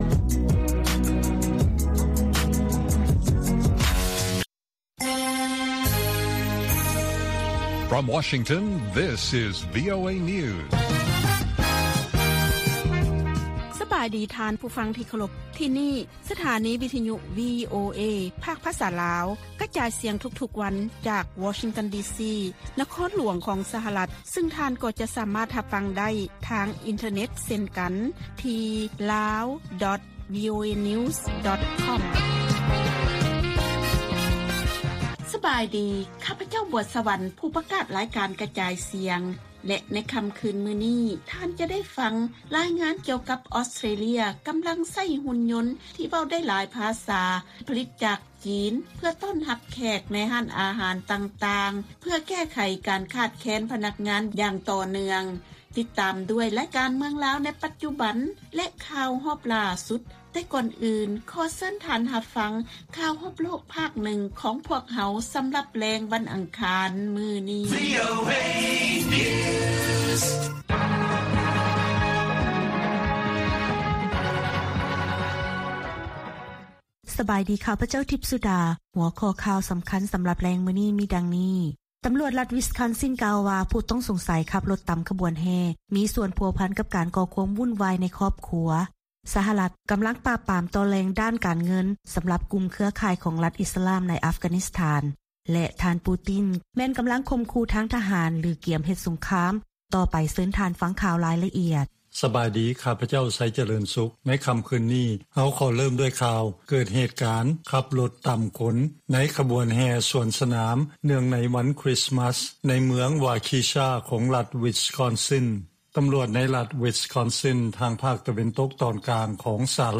ລາຍການກະຈາຍສຽງຂອງວີໂອເອ ລາວ: ຕຳຫຼວດລັດວິສຄອນຊິນ ກ່າວວ່າ ຜູ້ຕ້ອງສົງໄສຂັບລົດຕຳຂະບວນແຫ່ ມີສ່ວນພົວພັນກັບການກໍ່ຄວາມວຸ້ນວາຍໃນຄອບຄົວ